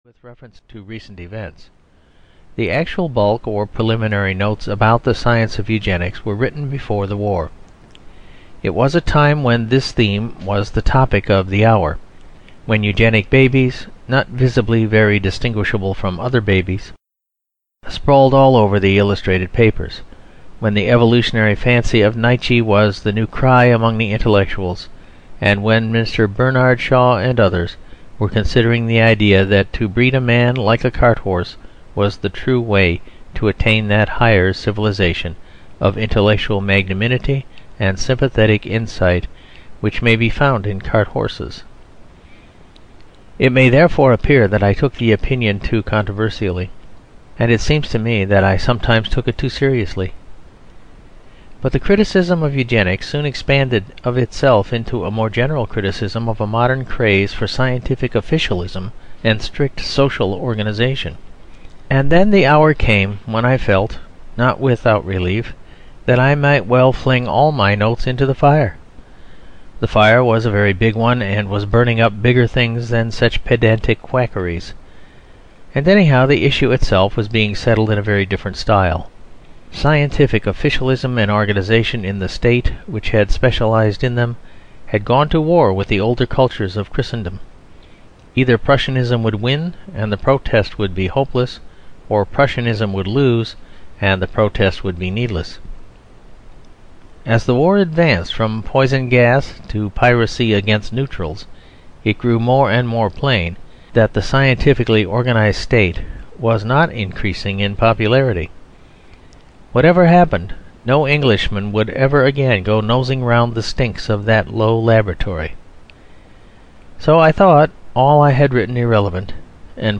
Eugenics and Other Evils (EN) audiokniha
Ukázka z knihy